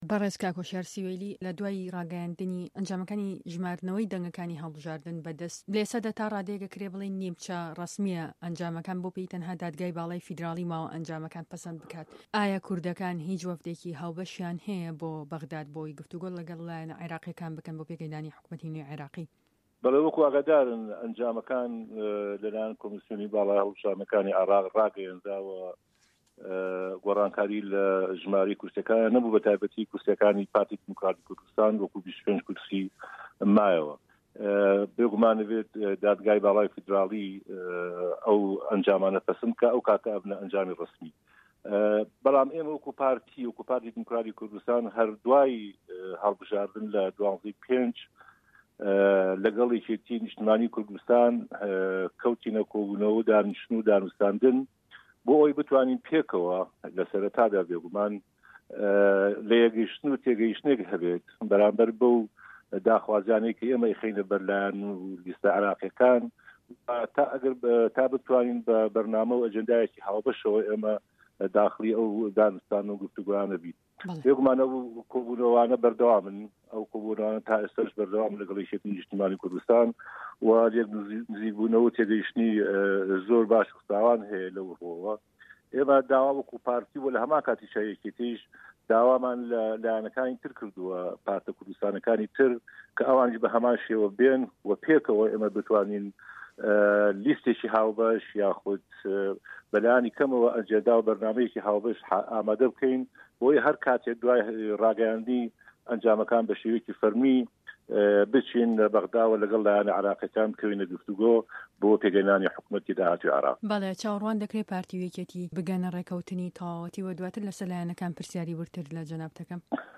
وتووێژه‌كه‌ی